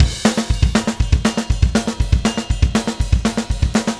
Power Pop Punk Drums 02.wav